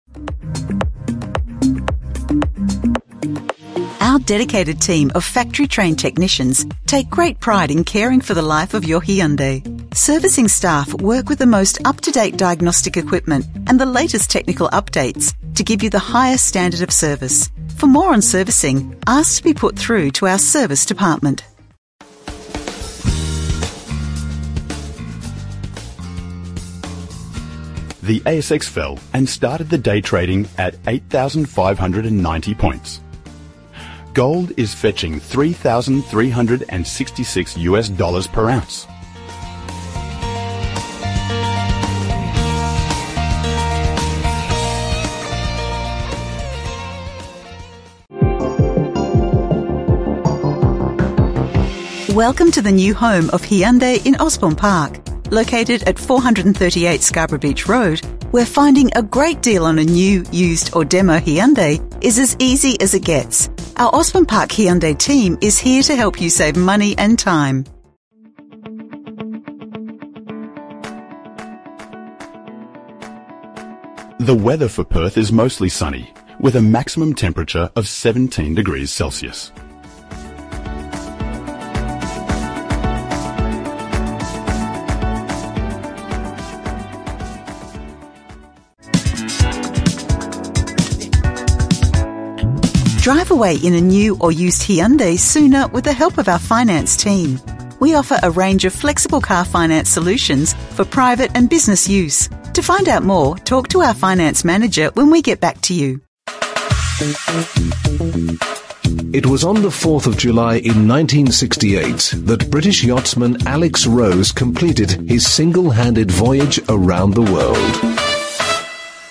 A better customer experience – On Hold